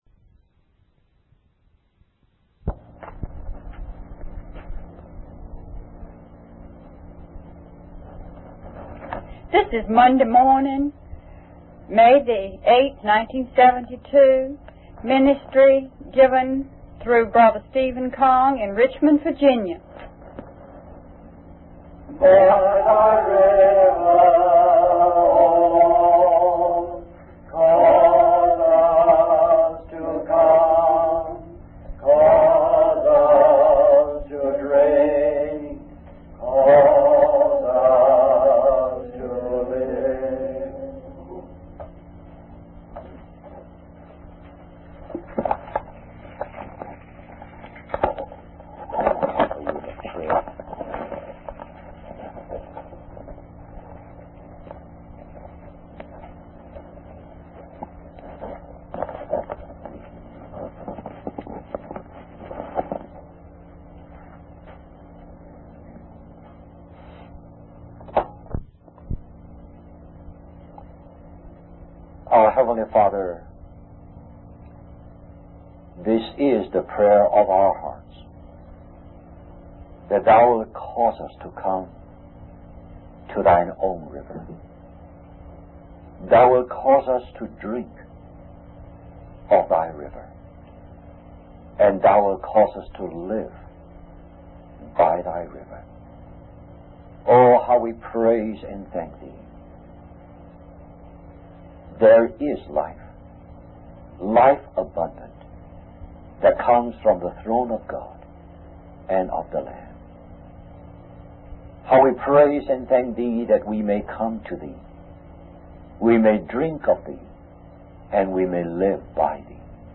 In this sermon, the speaker begins by praying for God to open His word to the listeners and make it alive in their lives. The main verse discussed is 1 Corinthians 4:1, which emphasizes the importance of viewing ourselves as servants and stewards of Christ and the mysteries of God.